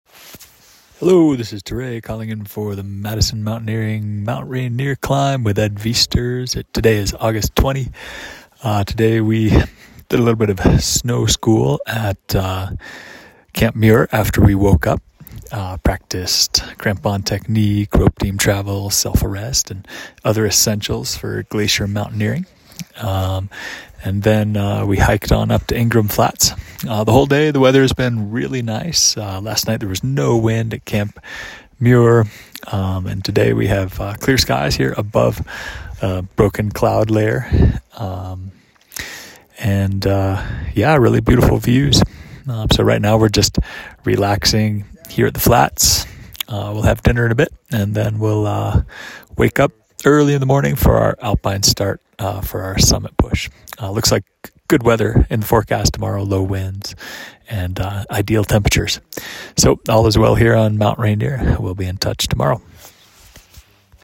checks in with this dispatch from Mount Rainier